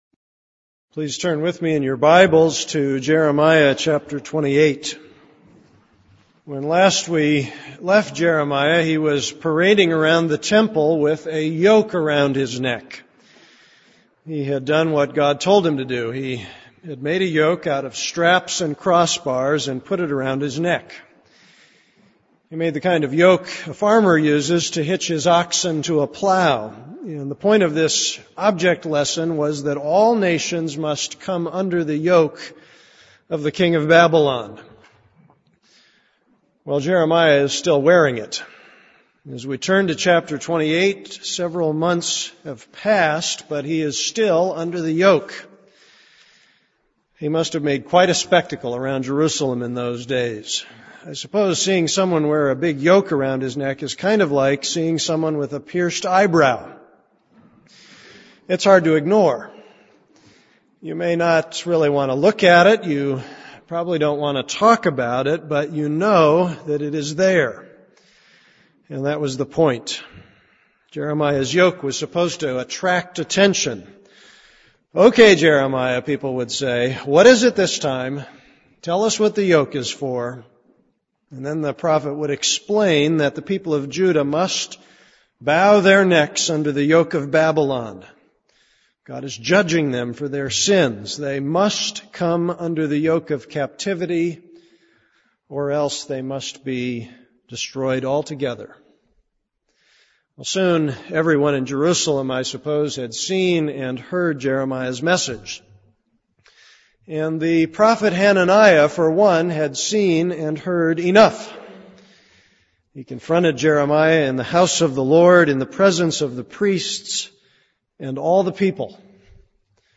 This is a sermon on Jeremiah 28:1-17.